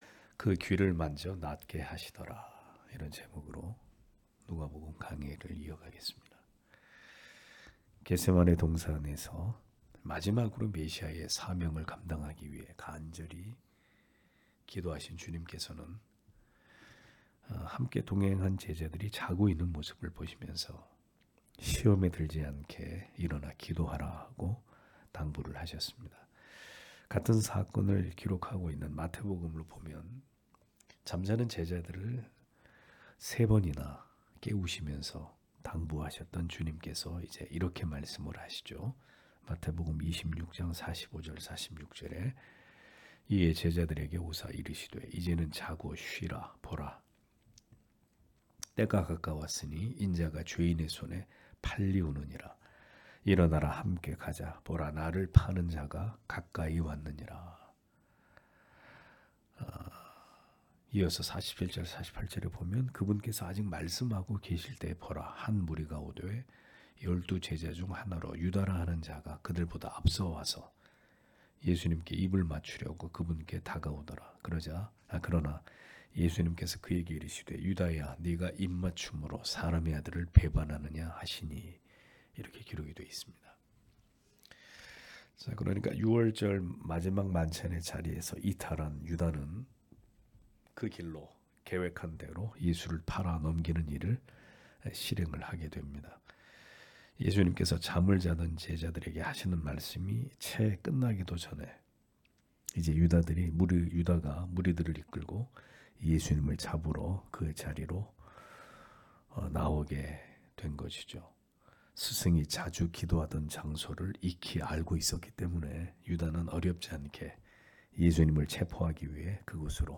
금요기도회